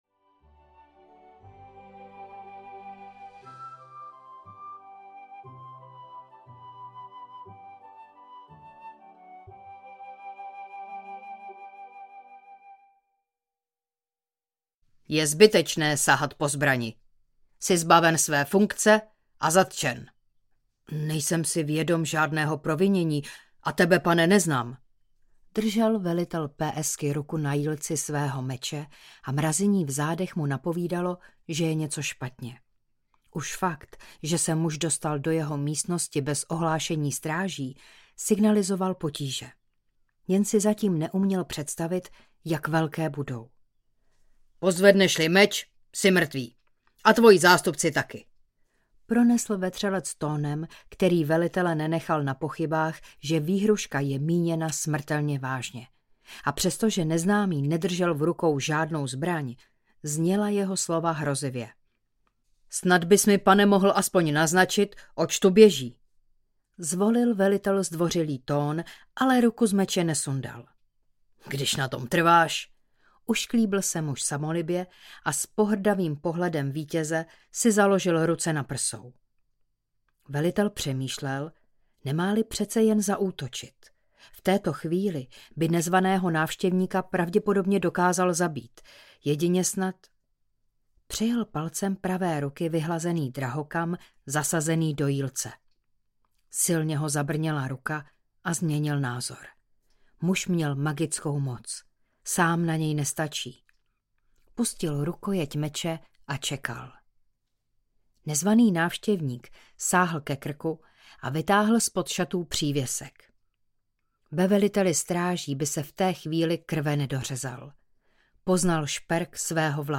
Zlodějíček z pátých hradeb audiokniha
Ukázka z knihy
zlodejicek-z-patych-hradeb-audiokniha